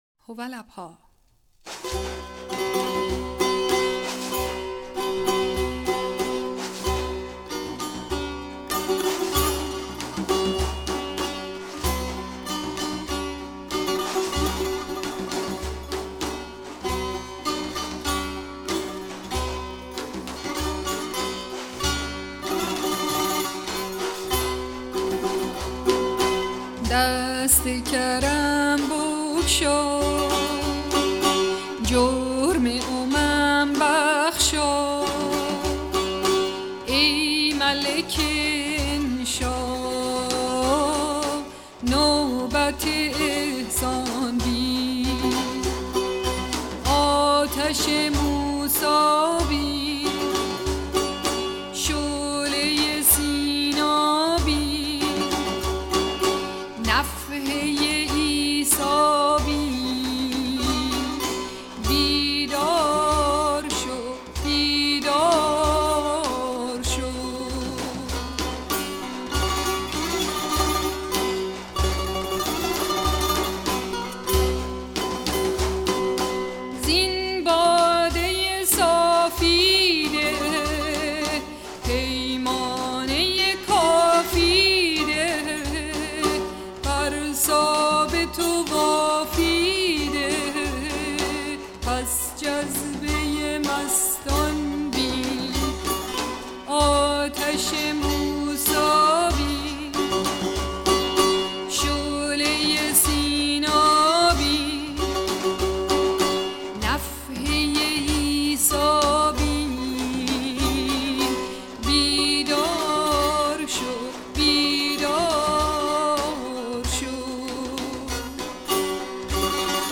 سرود - شماره 4 | تعالیم و عقاید آئین بهائی
مجموعه ای از مناجات ها و اشعار بهائی (سنتّی)